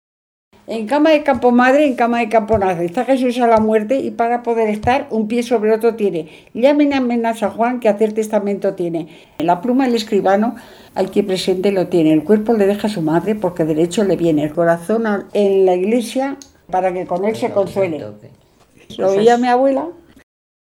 Clasificación: Romancero
Lugar y fecha de recogida: Baños de río Tobía, 30 de octubre de 2003